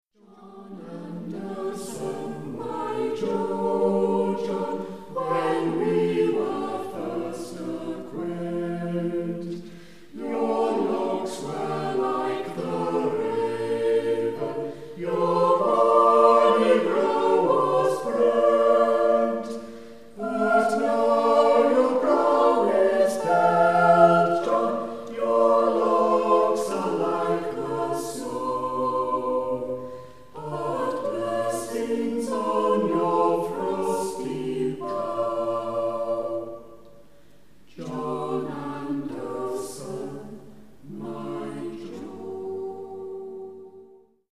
Musyck Anon, a cappella choir in Evesham, Worcestershire and Gloucestershire